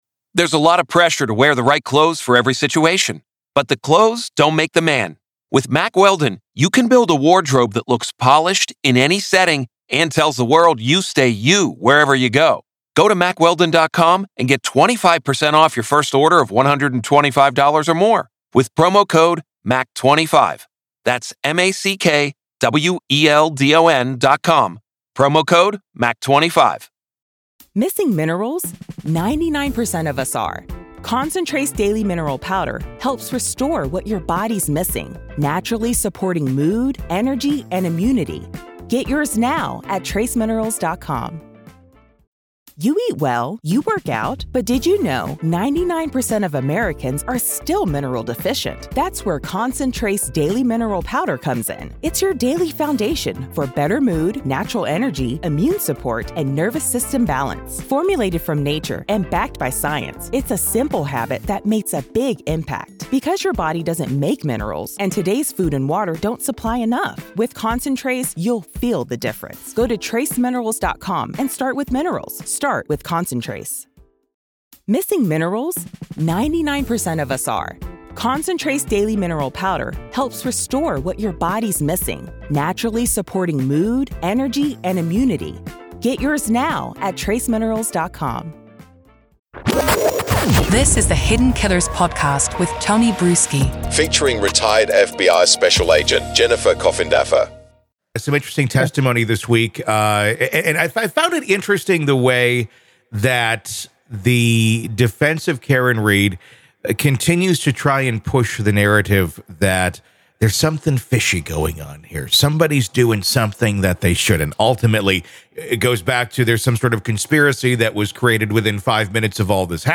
Main Points of the Conversation